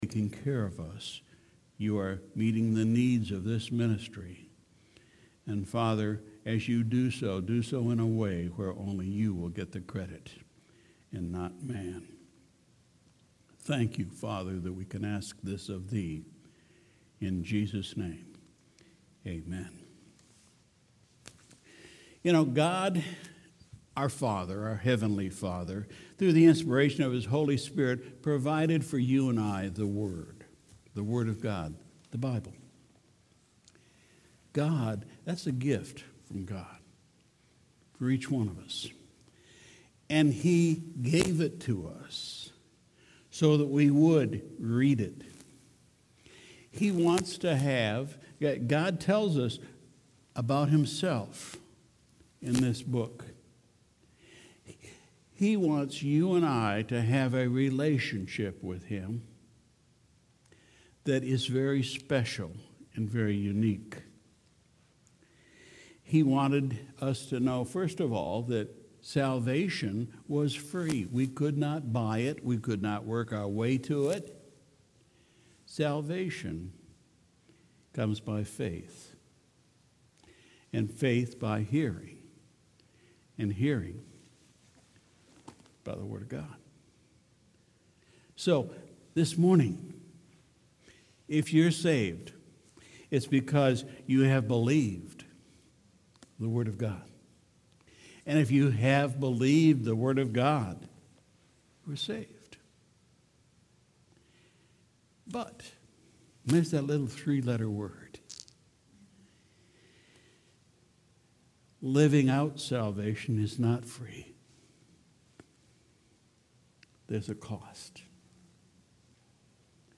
October 6, 2019 – Morning Service – “Which Way Do We Look?”